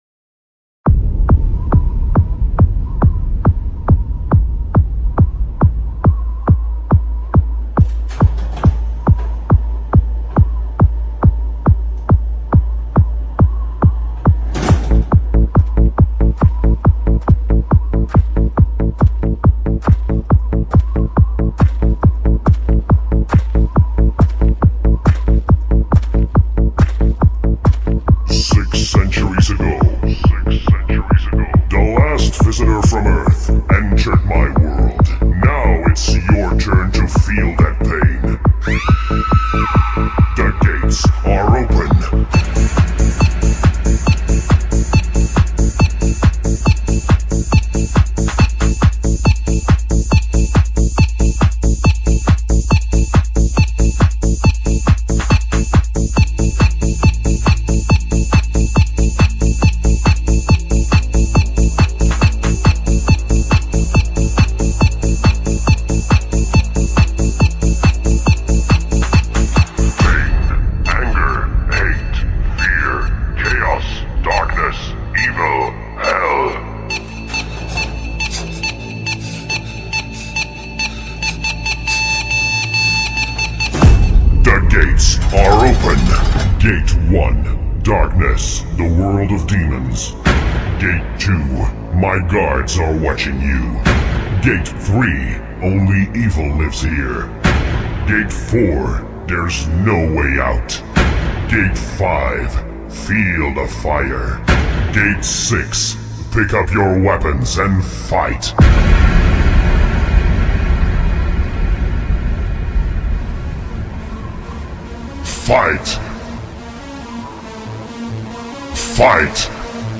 eletronica+-+batida+mostra.mp3